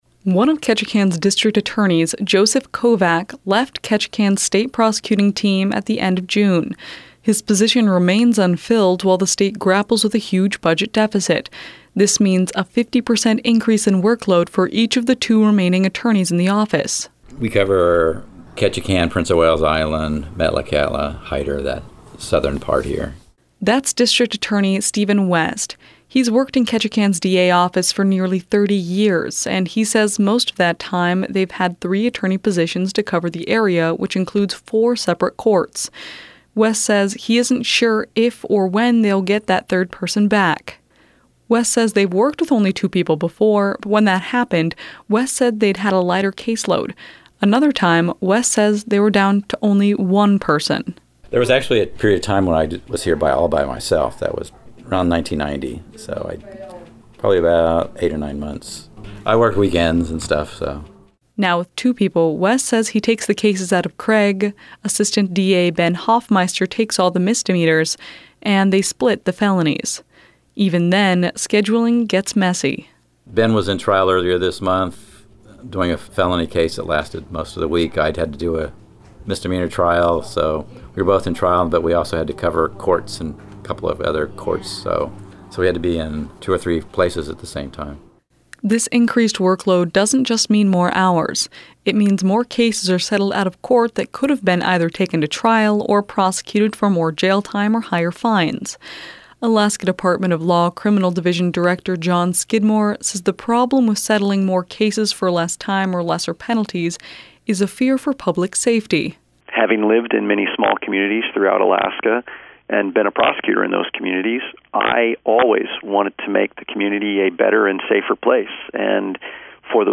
Did you appreciate this report?